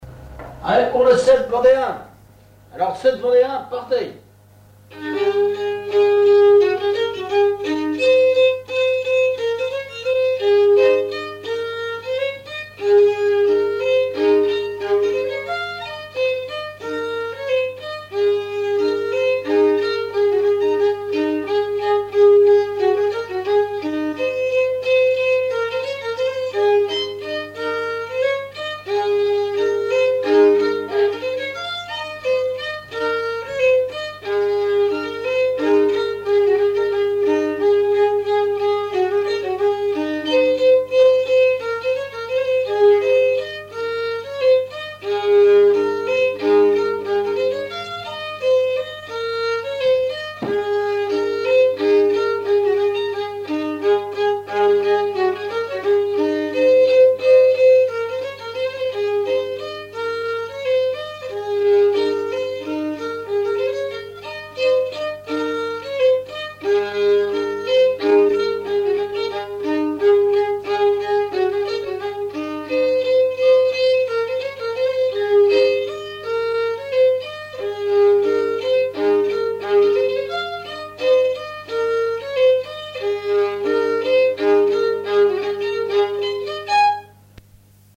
Chants brefs - A danser
danse : scottich sept pas
Auto-enregistrement
Pièce musicale inédite